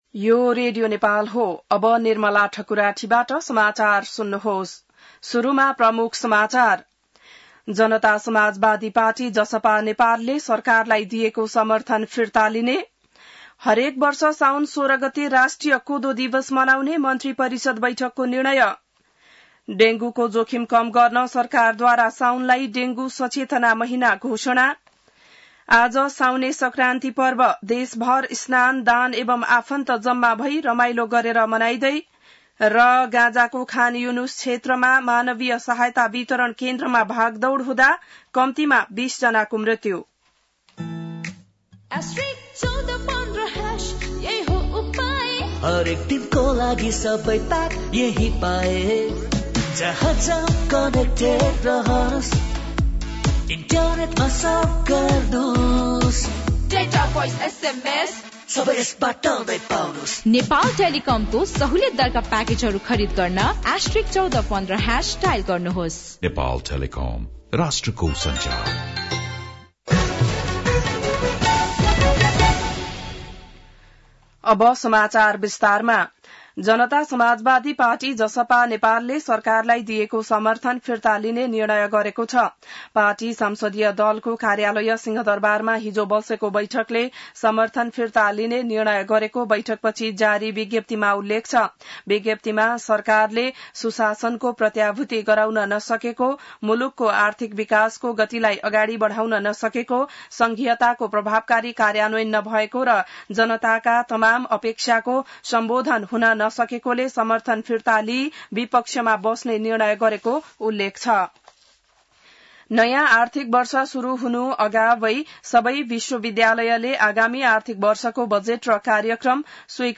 बिहान ७ बजेको नेपाली समाचार : १ साउन , २०८२